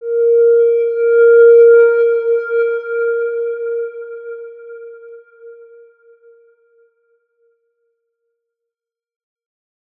X_Windwistle-A#3-pp.wav